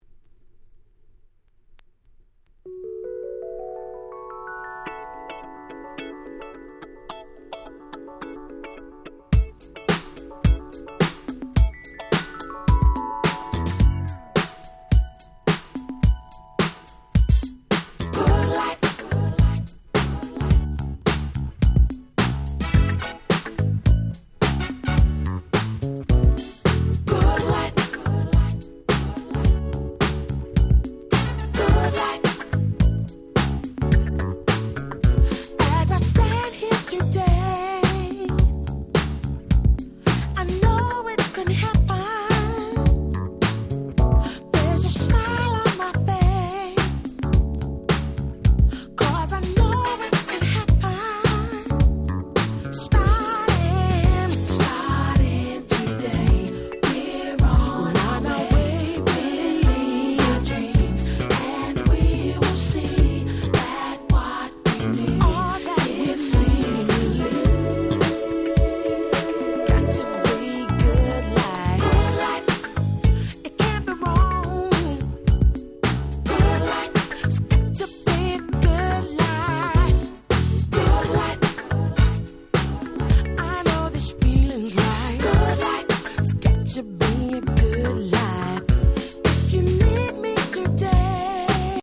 UK R&B Classic LP !!